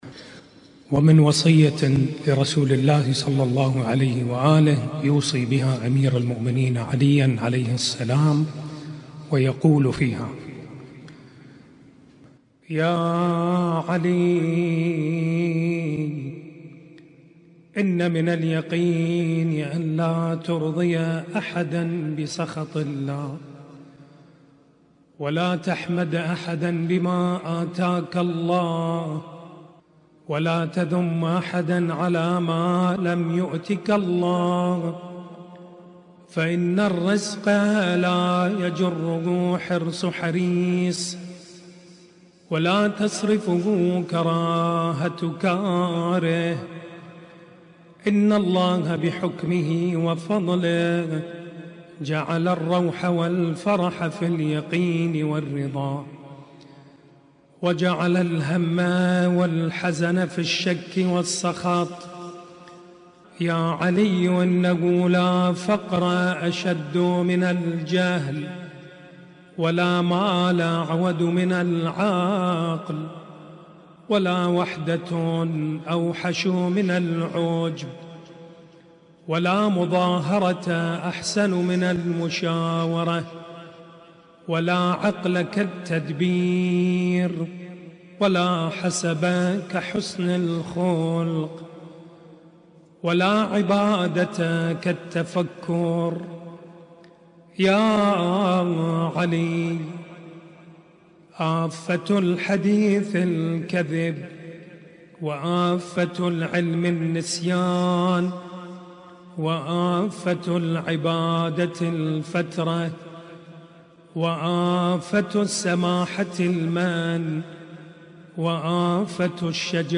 ليلة 5 محرم 1447هـــ